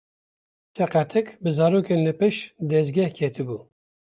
Pronounced as (IPA)
/pɪʃt/